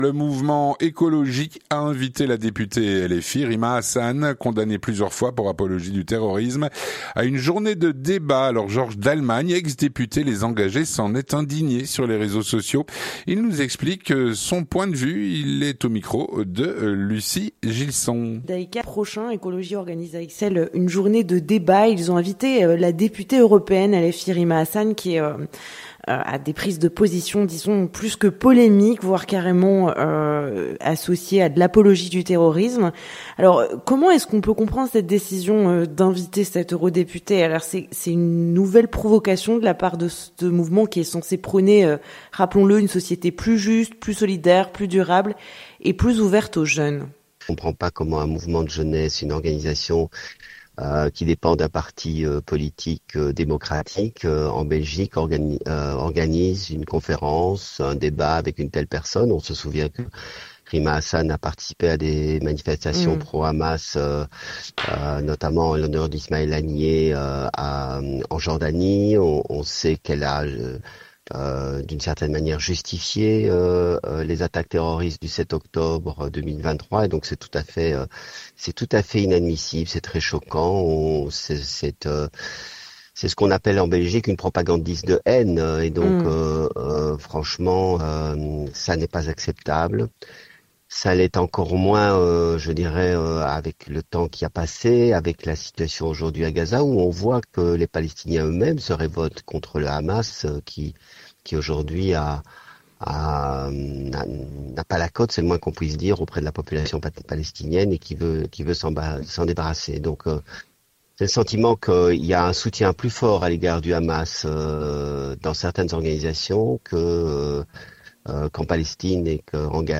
L'entretien du 18H - Le mouvement Ecoloj a invité la députée LFI Rima Hassan à une journée de débat.